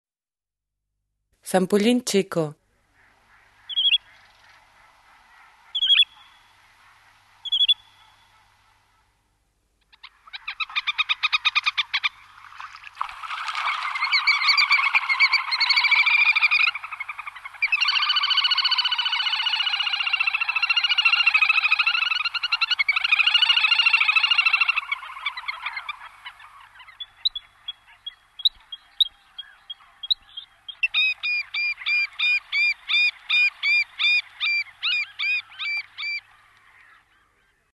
Малая поганка (Tachybaptus ruficollis (Pallas, 1764))
tachybaptus_ruficollis2.mp3